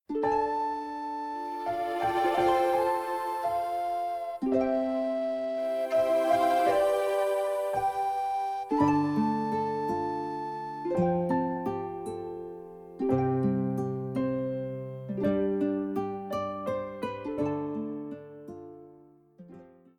SATB
Choral Concert/General